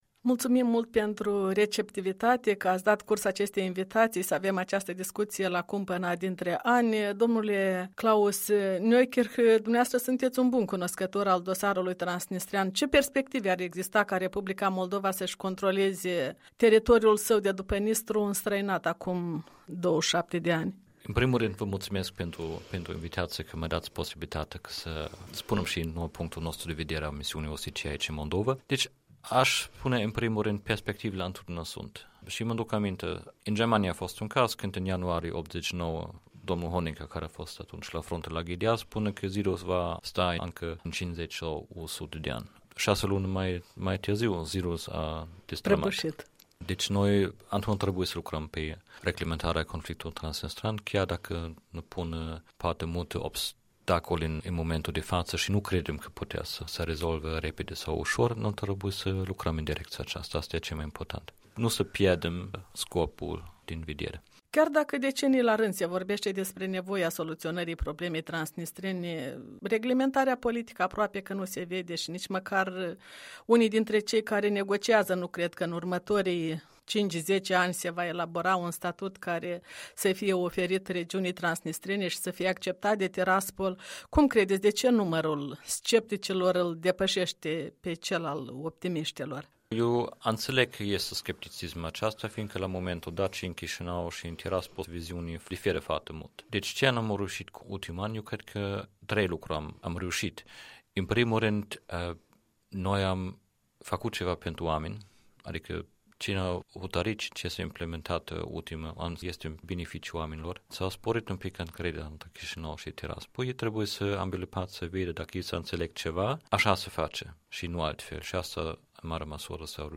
Un interviu cu șeful Misiunii OSCE în Moldova despre perspectivele procesului de reglementare transnistreană.